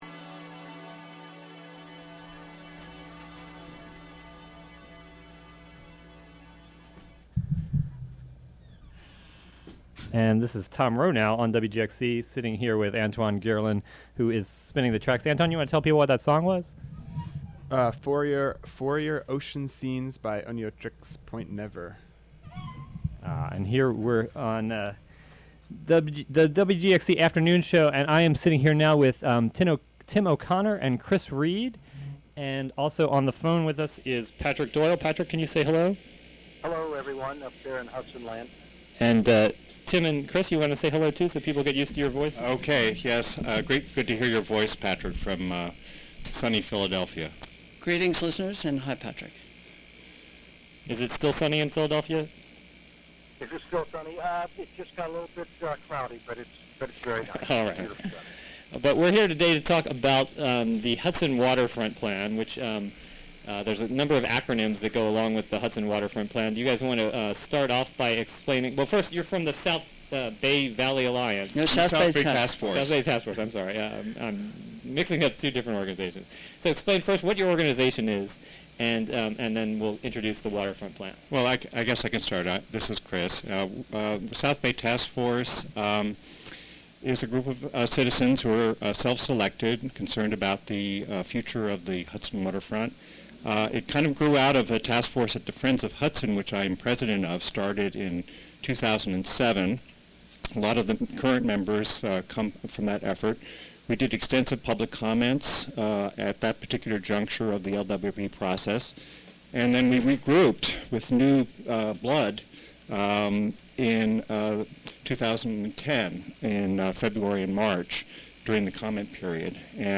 South Bay Task Force discussion about Hudson's waterfront plan.